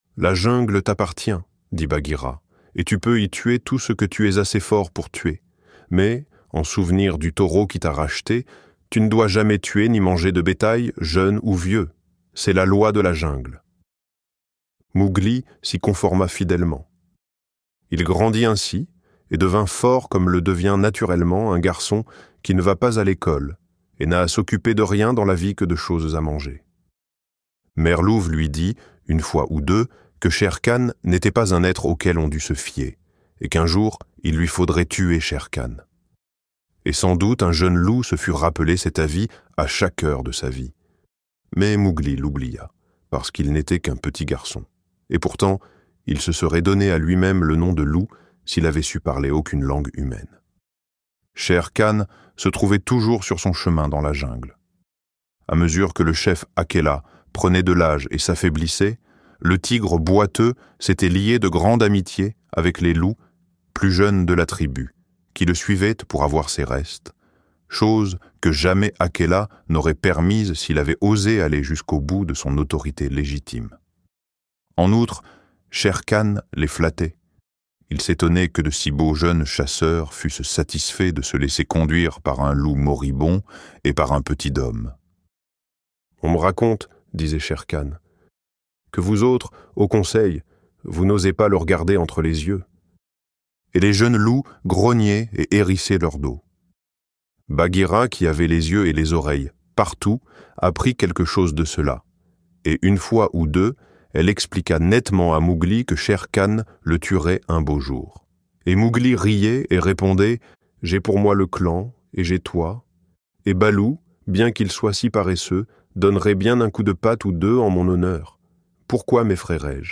Le Livre de la jungle - Livre Audio